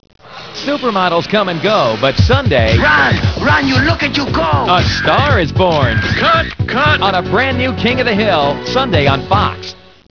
MIKE JUDGE as Hank Hill
PAMELA SEGALL as Bobby Hill
koh20510.mov (818k, Quicktime)   Audio Promo